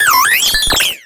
Cries
BERGMITE.ogg